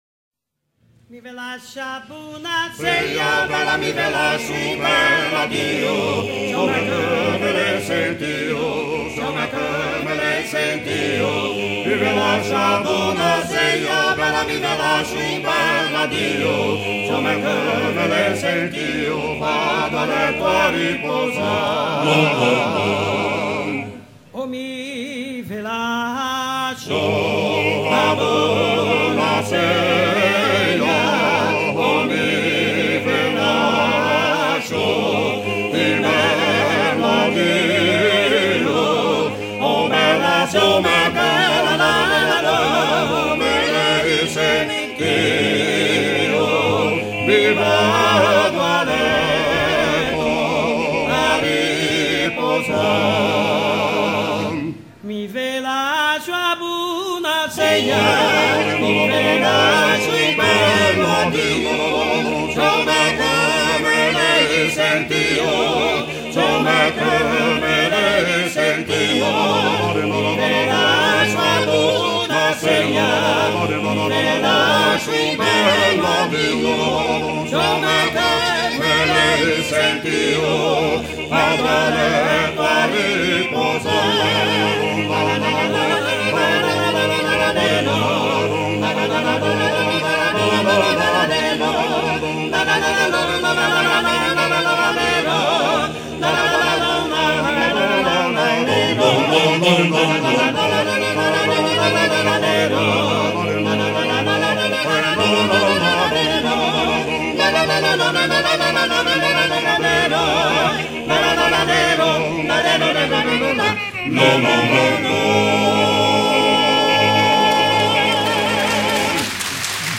Chanson du répertoire des dockers du port de Gènes, enregistrée lors de Paimpol 91